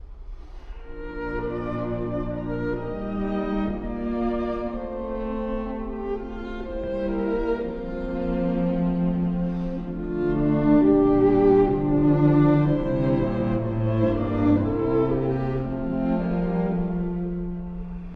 ↑古い録音のため聴きづらいかもしれません！
また、チェロのピチカートに乗せられて1stバイオリンが朗々と歌う場面は、えもいわれぬ郷愁を感じさせます。